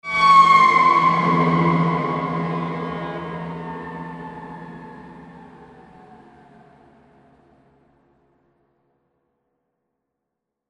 消防车鸣笛
描述：这是在我可爱的当地消防志愿者的帮助下录制的。没有多普勒，没有车辆引擎，没有街道噪音。对它有一点回应。包括3种不同的警笛声，可根据需要进行编辑。
标签： 紧急 消防车 警笛 警报器
声道立体声